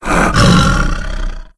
c_sibtiger_bat1.wav